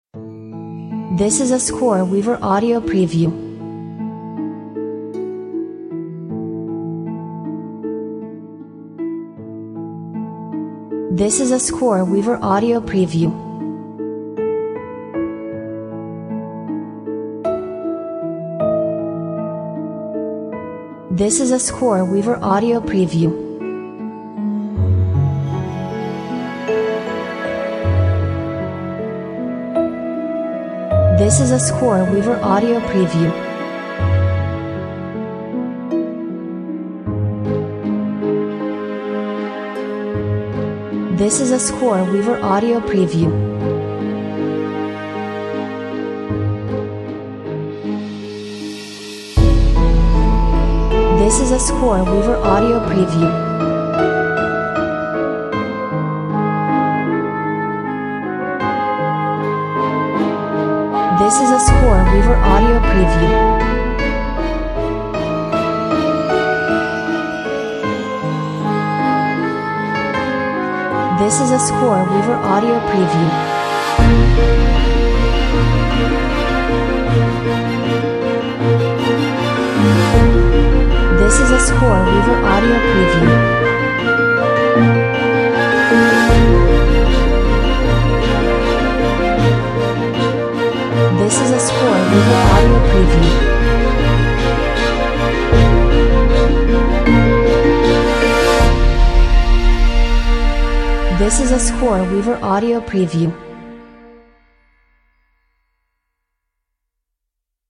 Features Piano, Bell Trees, Flutes, Oboe, Pizzicato strings…